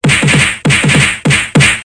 打成一团.mp3